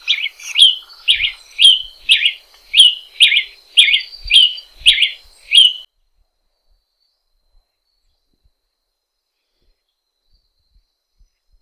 Lanio (Coryphospingus) cucullatus - Brasita de fuego